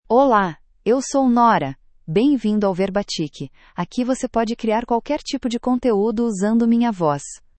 NoraFemale Portuguese AI voice
Nora is a female AI voice for Portuguese (Brazil).
Voice sample
Listen to Nora's female Portuguese voice.
Female
Nora delivers clear pronunciation with authentic Brazil Portuguese intonation, making your content sound professionally produced.